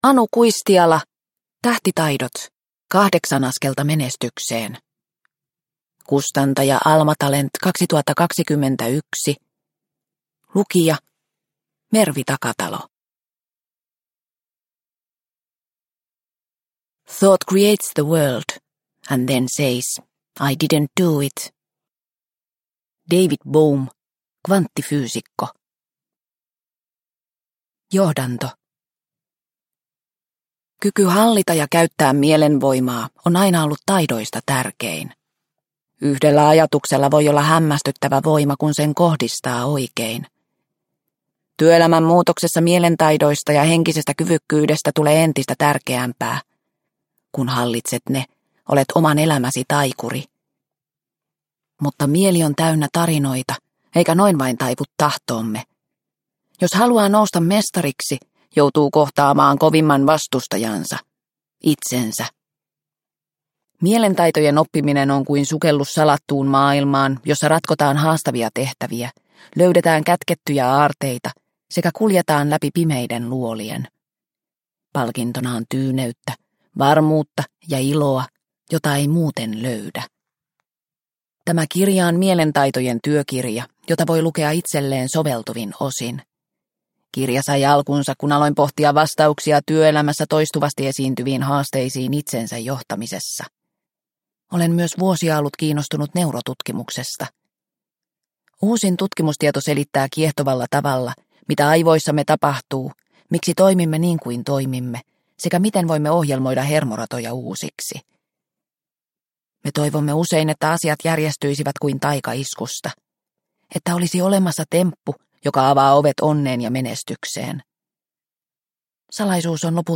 Tähtitaidot – Ljudbok – Laddas ner